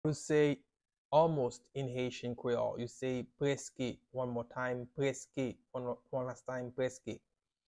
“Almost” in Haitian Creole – “Prèske” pronunciation by a native Haitian teacher
“Prèske” Pronunciation in Haitian Creole by a native Haitian can be heard in the audio here or in the video below: